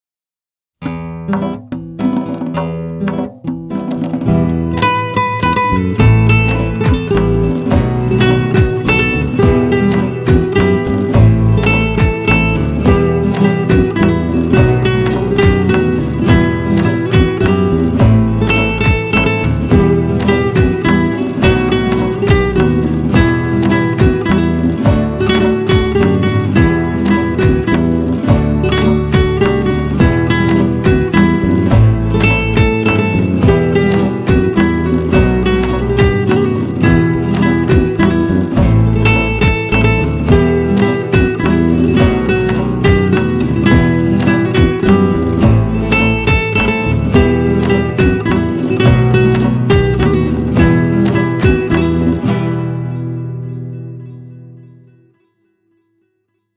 چالش نوروزی (بدون وکال)